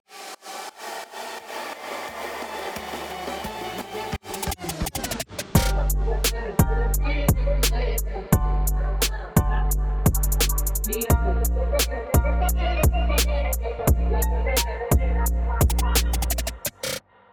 לרגל מוצאי תשעה באב - תאונה בין מוזיקה חסידית להיפ הופ ריקוד הפינגוונים היפ הופ.wav